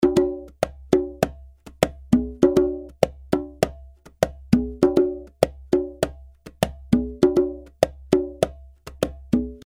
100 bpm conga loops (15 variations)
Real conga loops played by professional percussion player in 100 BPM.
🪘 Authentic Conga Loops – Salsa Style – 100 BPM 🪘
Spice up your productions with 15 vibrant conga loops, played by a professional percussionist and tailored for Latin Salsa grooves.